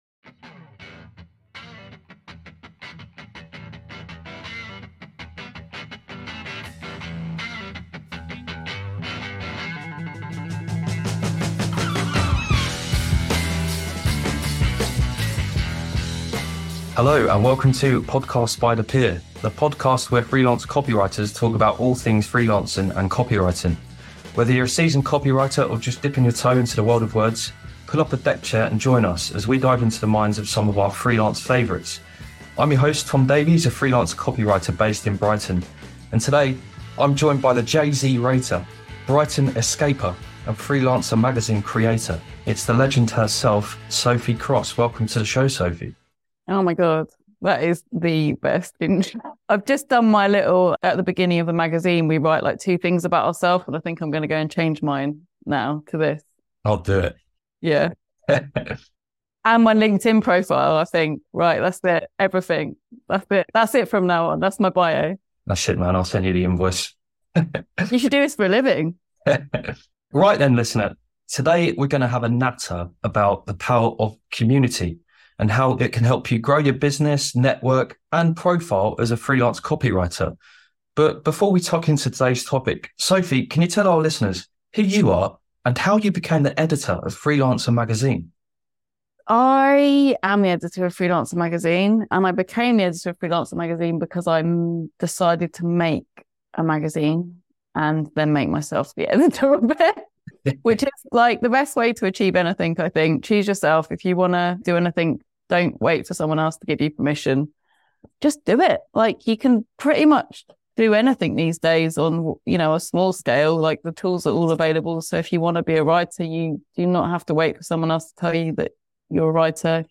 'Podcast by the pier' is a show where freelance copywriters talk about all things freelancing and copywriting.